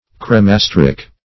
cremasteric - definition of cremasteric - synonyms, pronunciation, spelling from Free Dictionary
Search Result for " cremasteric" : The Collaborative International Dictionary of English v.0.48: Cremasteric \Crem`as*ter"ic\ (kr?m`3s-t?r"?k), a. (Anat.)